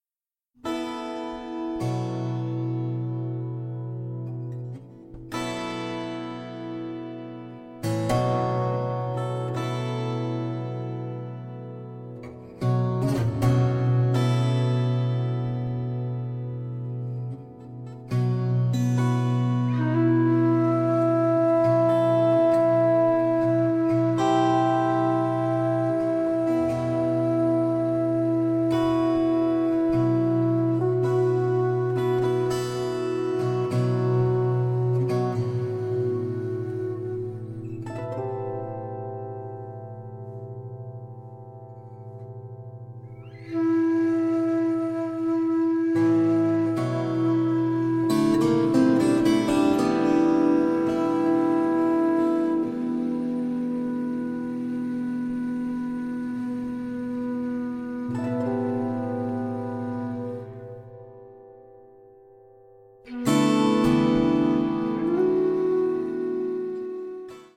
Tenor and Soprano saxophones, Alto flute, Bansuri flute